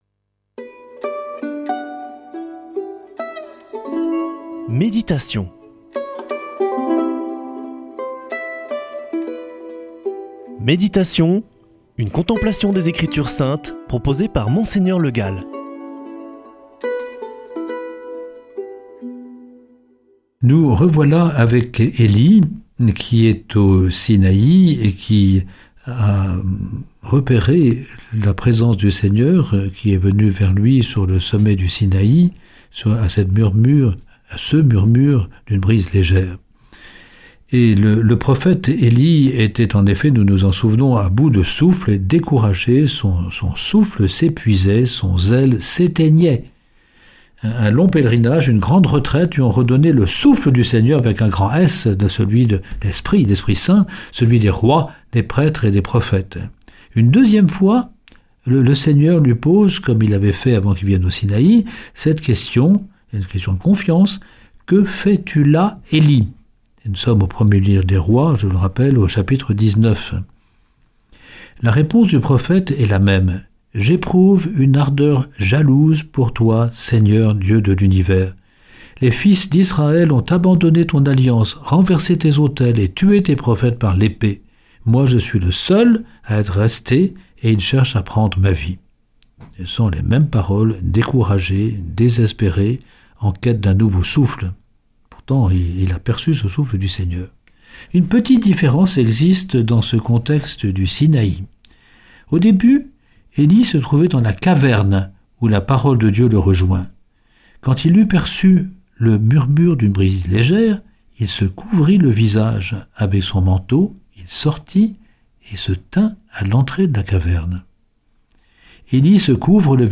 Méditation avec Mgr Le Gall
Monseigneur Le Gall
Présentateur